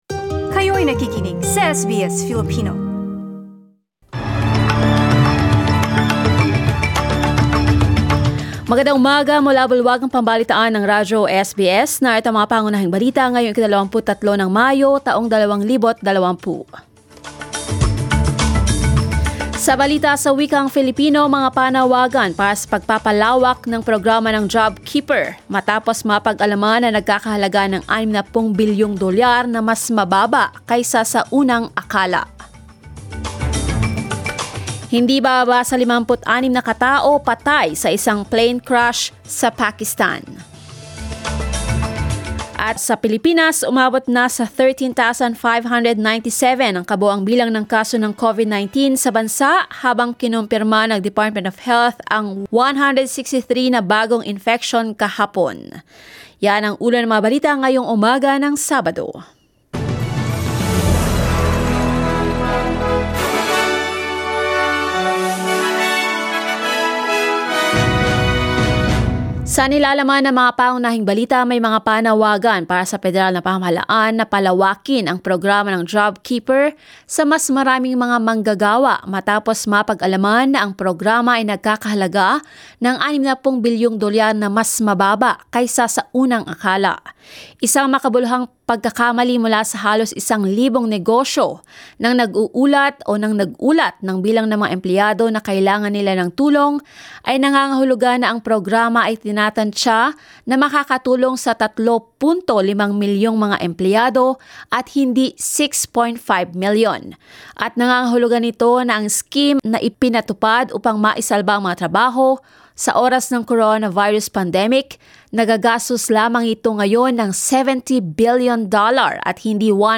SBS News in Filipino, Saturday 23 May